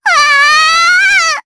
Rehartna-Vox_Damage_jp_03.wav